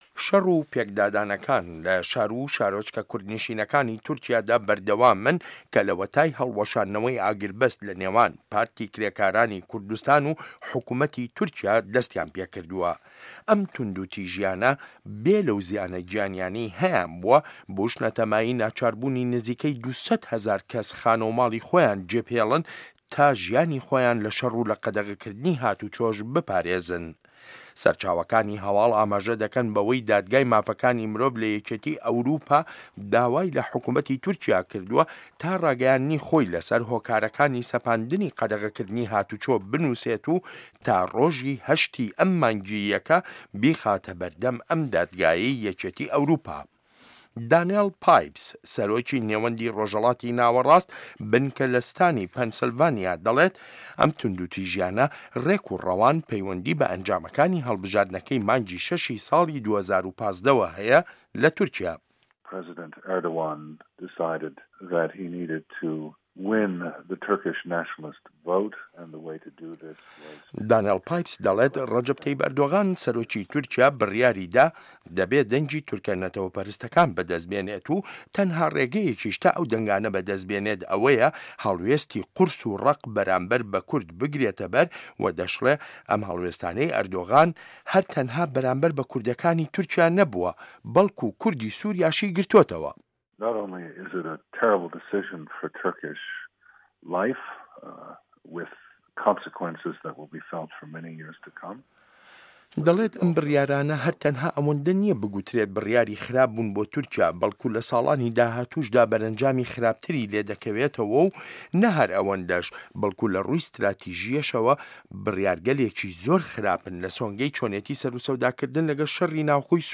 ڕاپۆرت لەسەر بنچینەی لێدوانەکانی دکتۆر دانێڵ پایپس